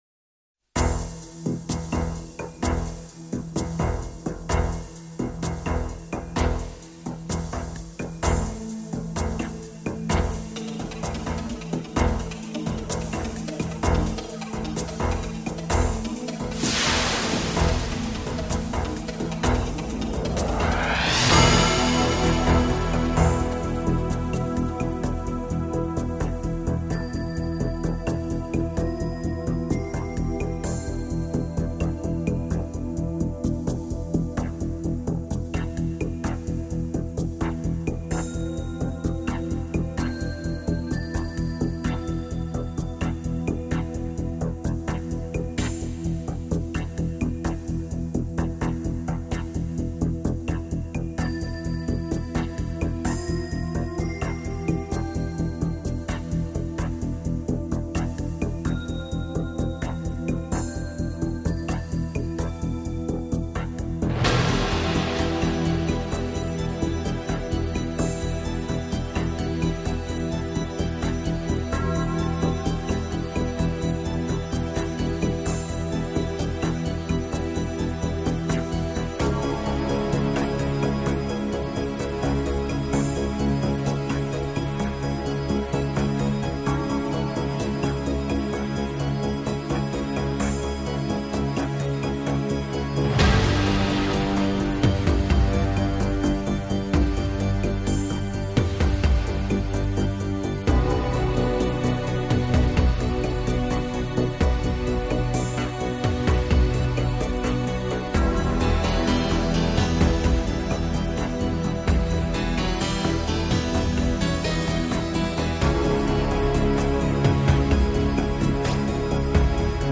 tout synthé très eighties